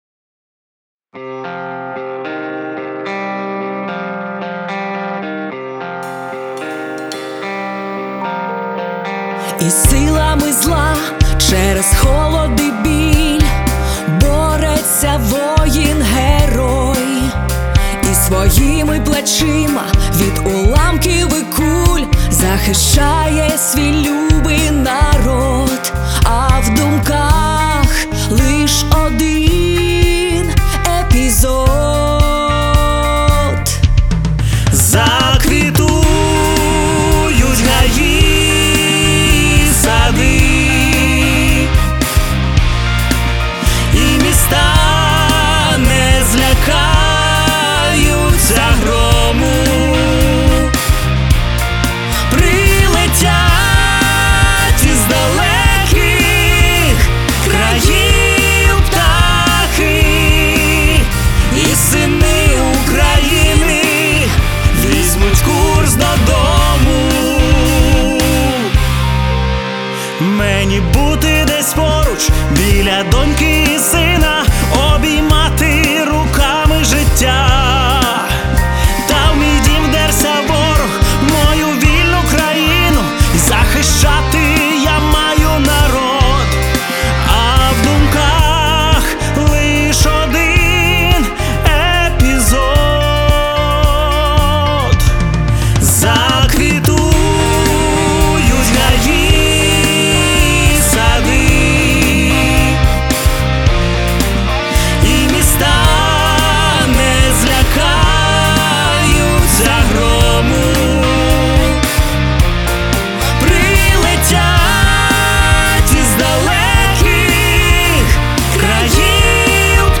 Всі мінусовки жанру Pop-Rock
Плюсовий запис